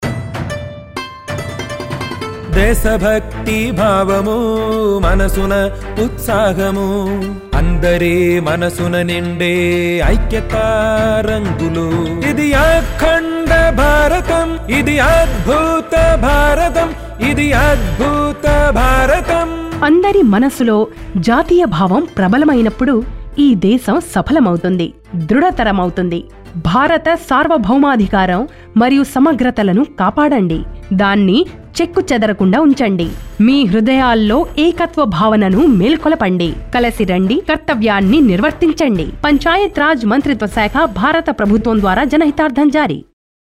225 Fundamental Duty 3rd Fundamental Duty Protect sovereignty & integrity of India Radio Jingle Telugu